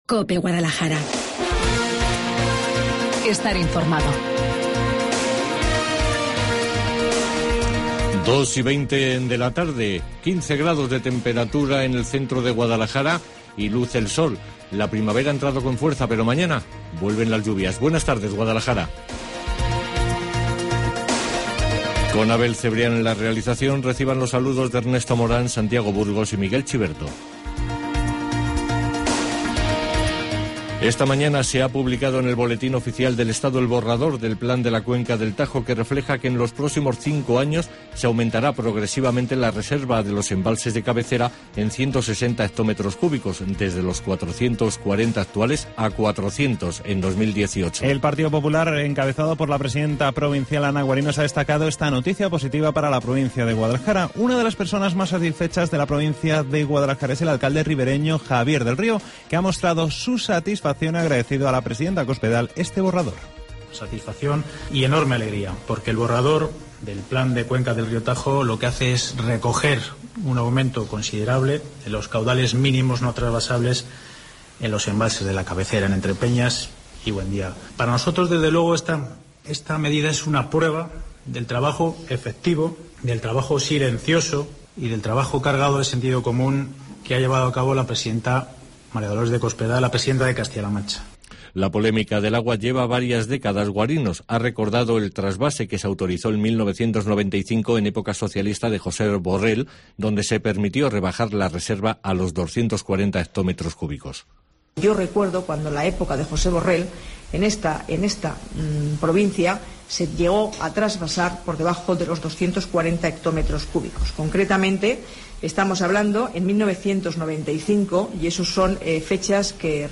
Informativo Guadalajara 21 de marzo